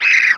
CREATURE_Squeel_06_mono.wav